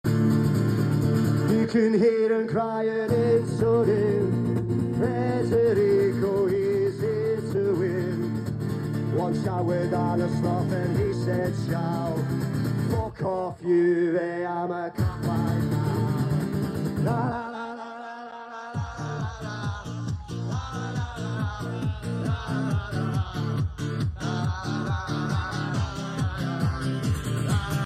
KOPITES sing Federico Chiesa song sound effects free download